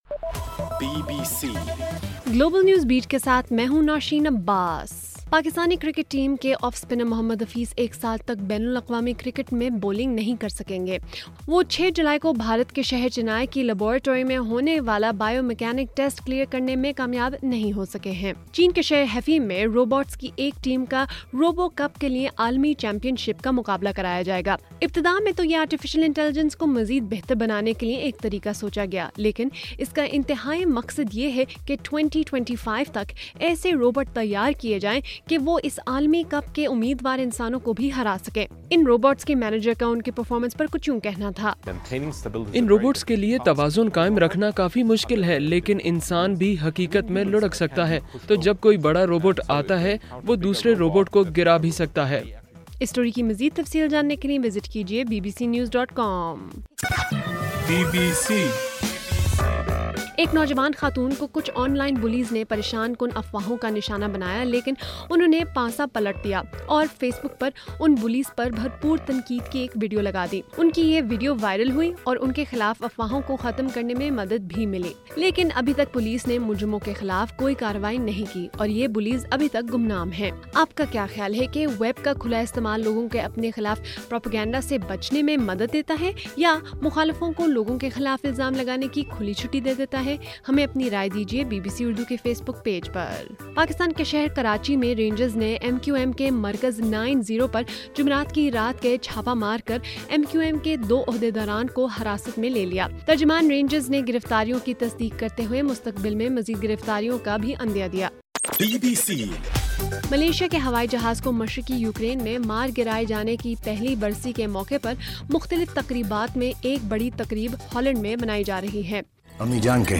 جولائی 17: رات 9 بجے کا گلوبل نیوز بیٹ بُلیٹن